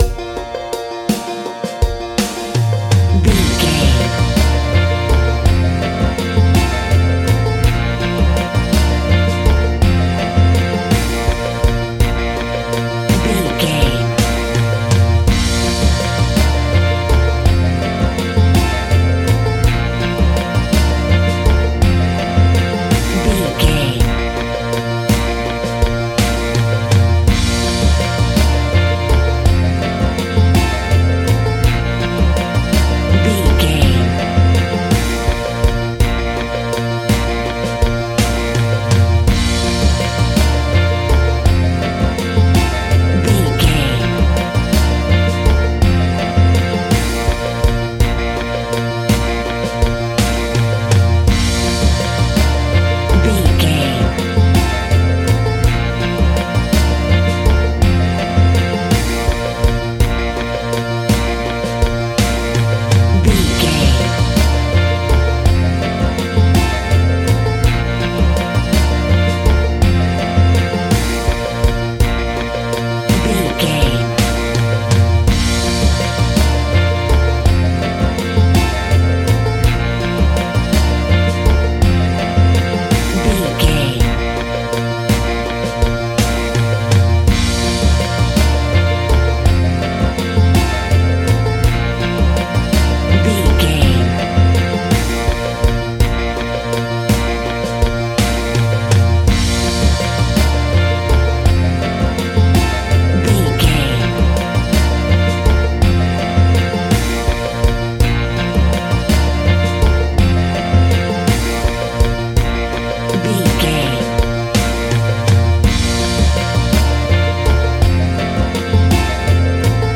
Ionian/Major
B♭
SEAMLESS LOOPING?
DOES THIS CLIP CONTAINS LYRICS OR HUMAN VOICE?
Slow